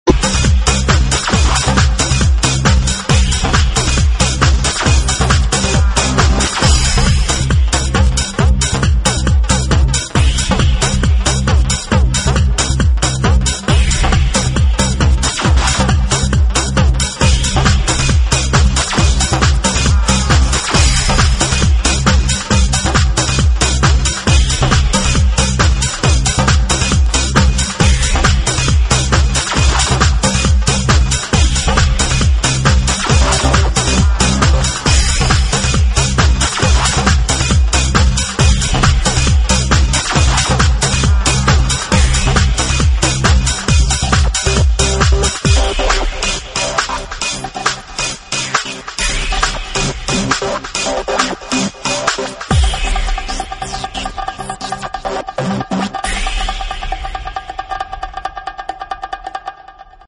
dj club DJ舞曲